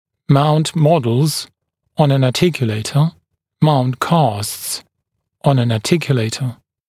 [maunt ‘mɔdlz (kɑːsts) ɔn ən ɑːˈtɪkjuleɪtə]